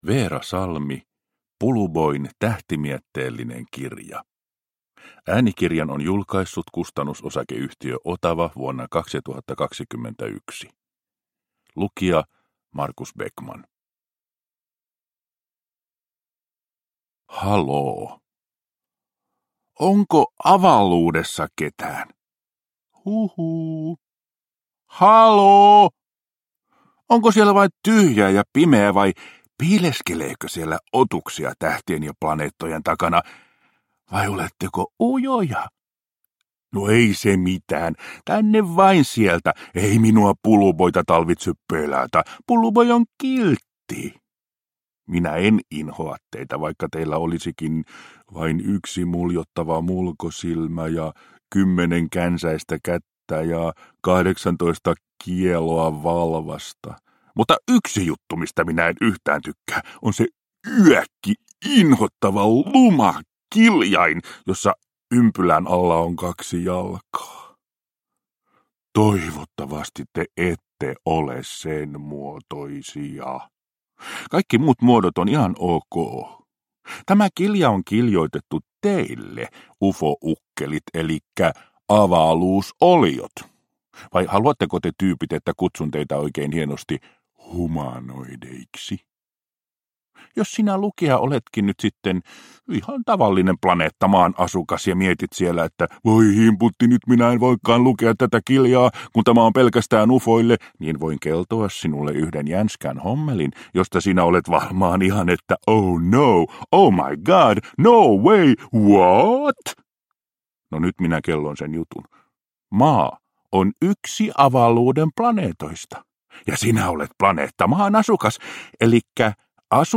Puluboin tähtimietteellinen kirja – Ljudbok – Laddas ner